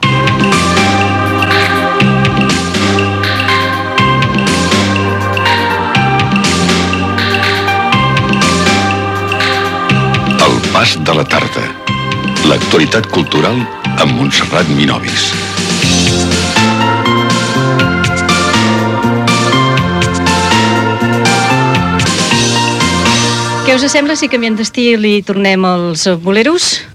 Indicatiu del programa i esment als boleros.